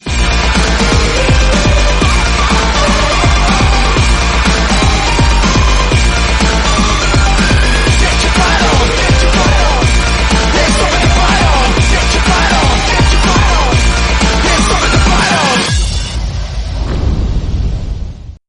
EA Sports UFC ambiance.mp3 (144.9 Ko)